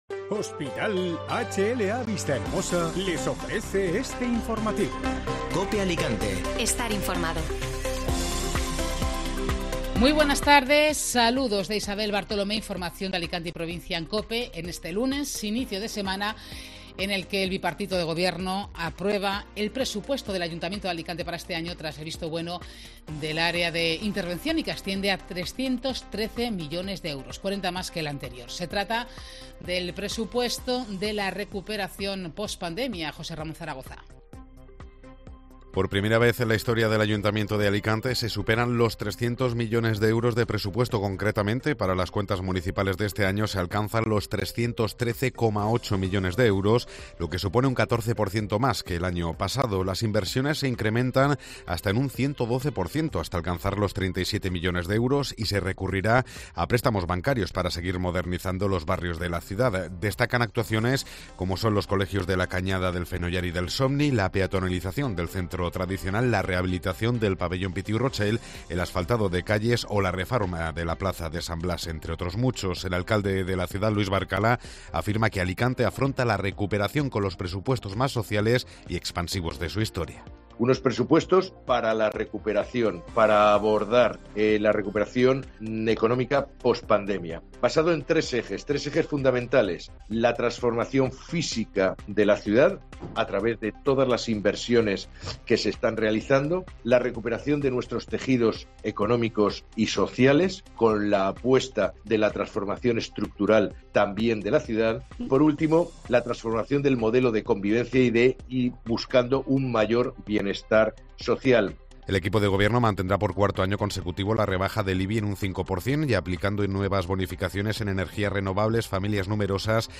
Informativo Mediodía COPE Alicante (Lunes 24 de enero)